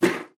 Звуки для игр
Ударился лбом о стену